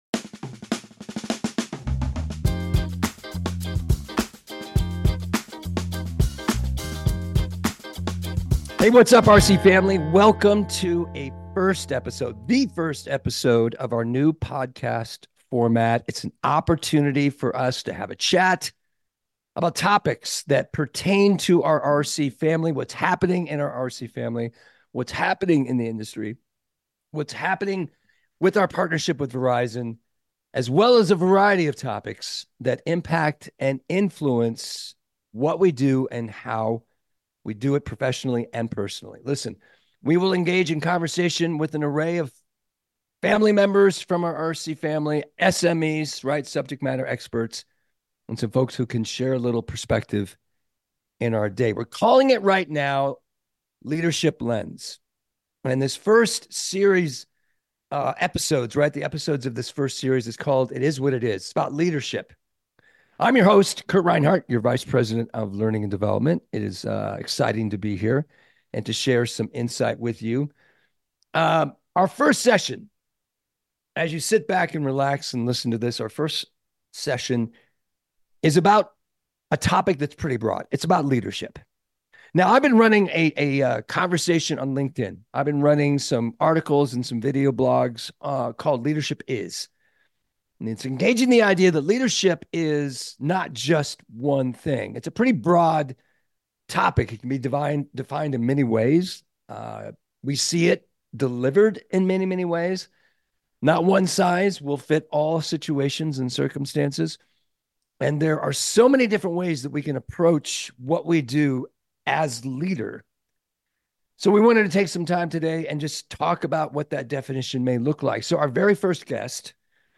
Explore the latest leadership with the Leaders Lens podcast. Hear interviews with top executives and business leaders on growth, company culture, and development within the wireless industry.